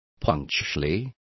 Complete with pronunciation of the translation of punctually.